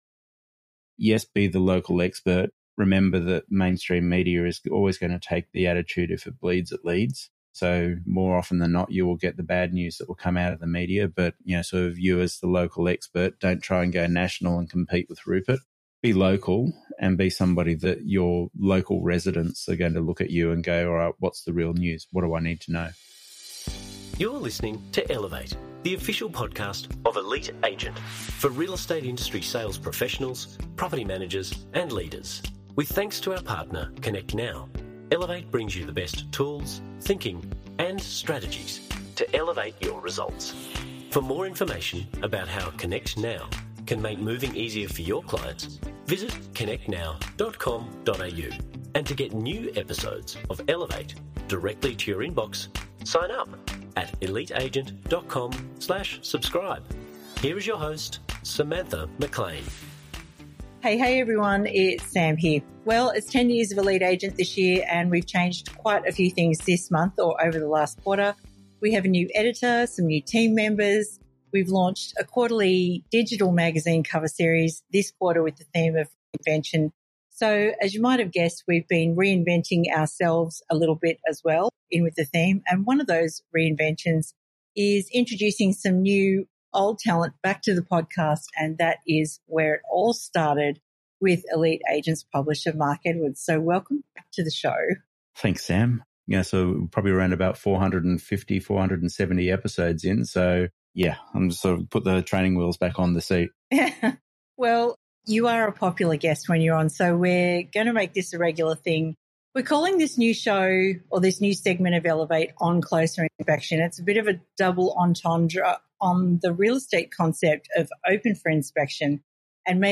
In this debut episode, the husband-and-wife team sit down together to tackle real estate’s spiciest conundrums (with actionable takeaways you can use immediatel…